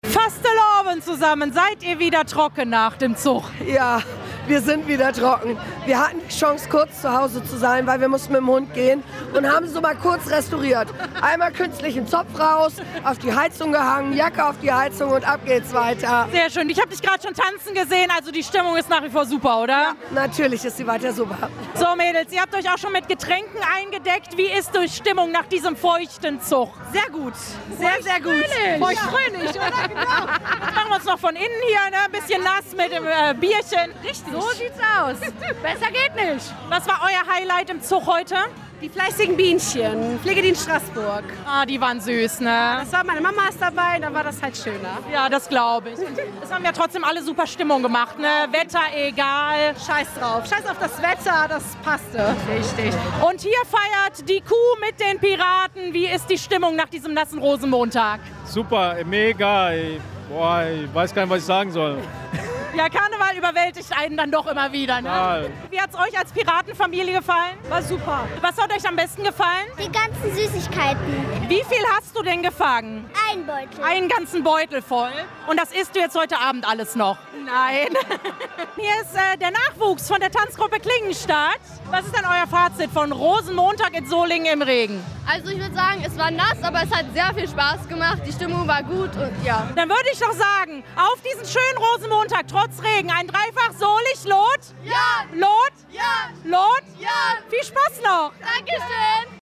After Zoch Party in Solingen
Trocken legen, warmtanzen und weiterfeiern konnten die Solinger Jecken auf der After-Zoch-Party im Theater und Konzerthaus. Hier ging es noch bis in den späten Abend hoch her.
Radio RSGSo war die Stimmung aud der After-Zoch-Party 2026
repo_after_zoch_party.mp3